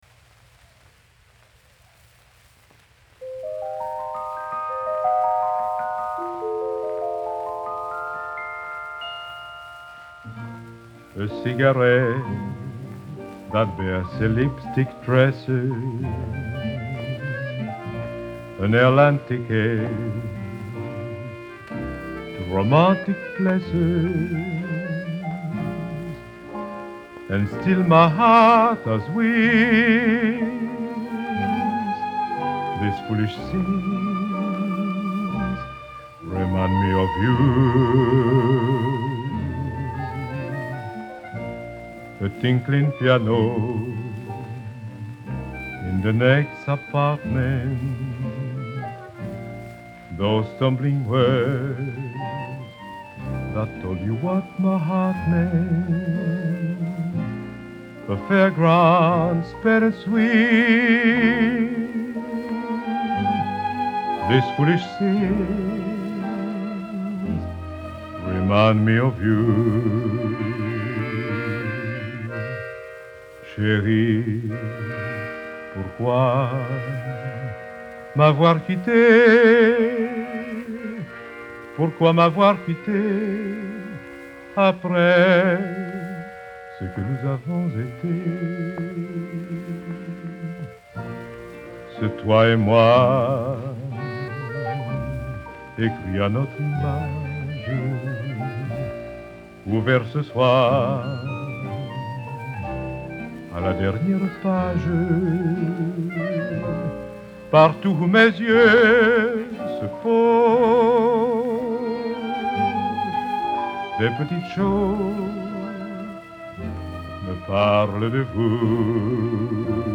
очень романтический стиль.